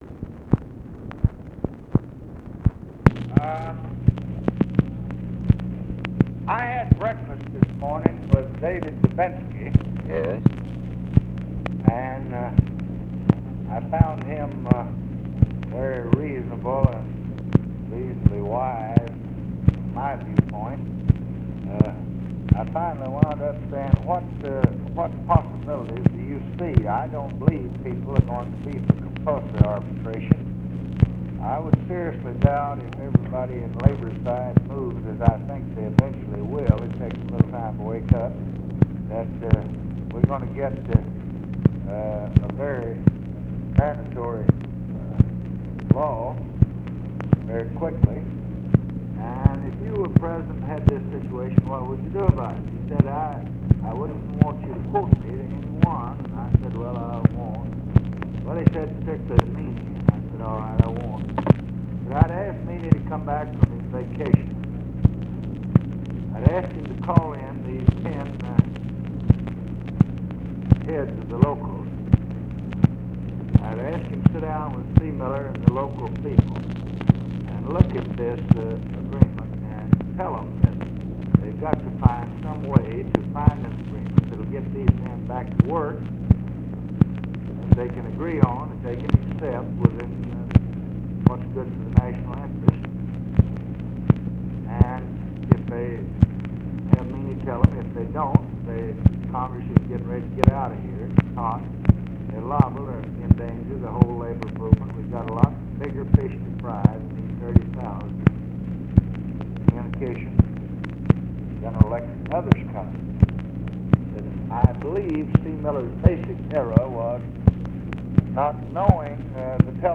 Conversation with WILLARD WIRTZ, August 3, 1966
Secret White House Tapes